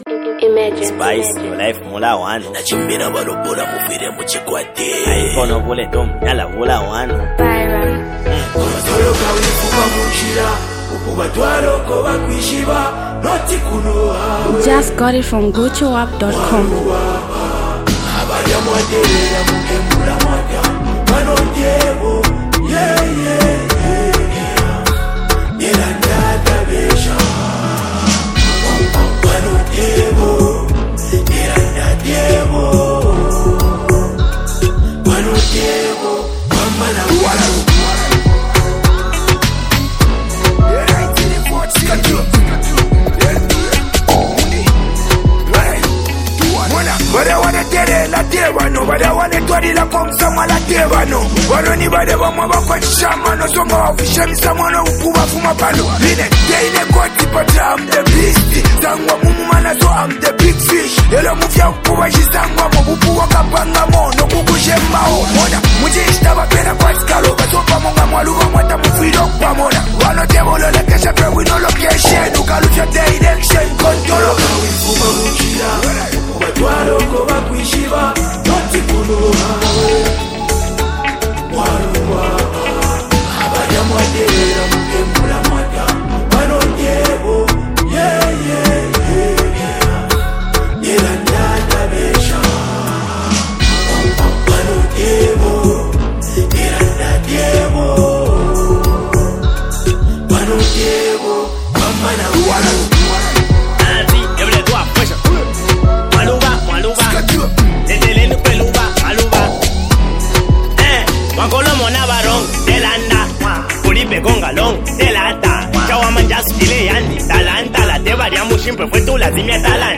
Zambian Mp3 Music
Copperbelt duo hardcore rappers
buzzing street anthem